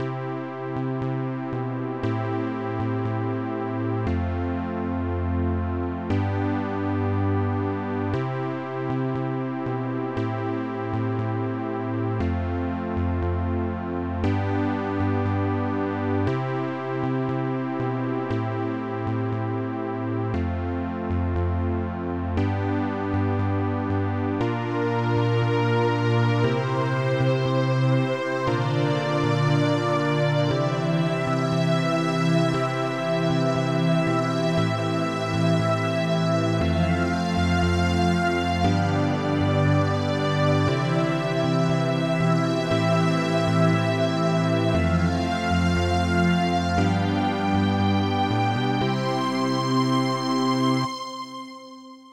Bakgrund 2 med synth
Bakgrund-2_Synth_Fusion-lead-synth-strings.mp3